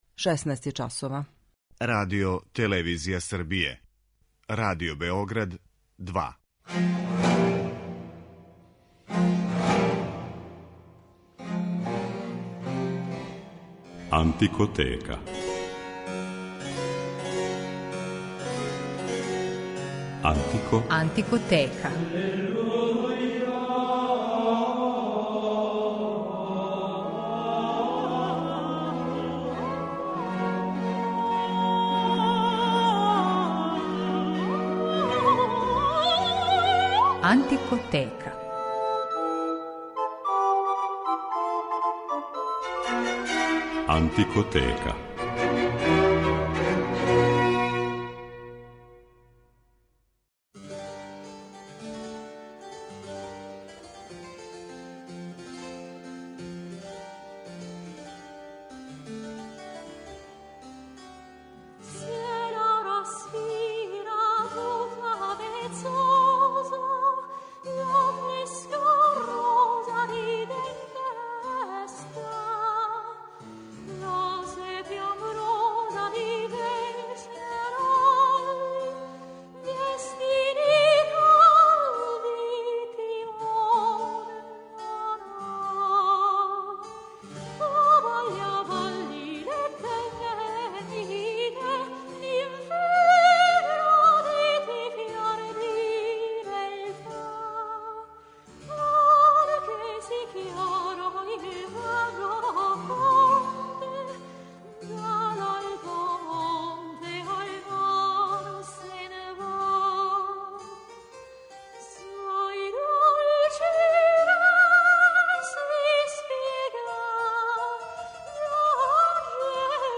Мушки сопран - најређа врста гласа на свету